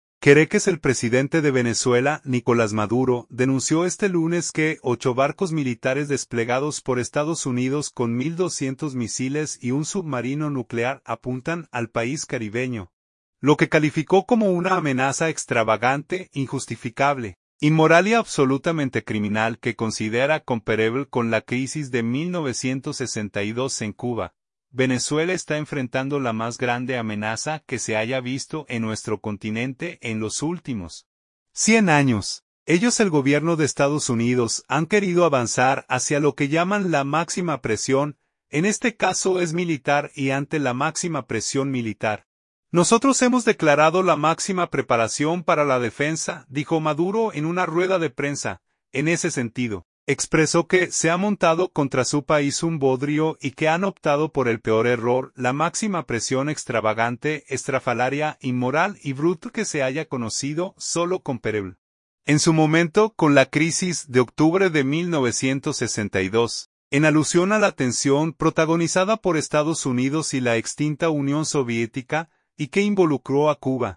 “Venezuela está enfrentando la más grande amenaza que se haya visto en nuestro continente en los últimos 100 años. (…) Ellos (el Gobierno de EE.UU.) han querido avanzar hacia lo que llaman la máxima presión, en este caso es militar, y ante la máxima presión militar, nosotros hemos declarado la máxima preparación para la defensa”, dijo Maduro en una rueda de prensa.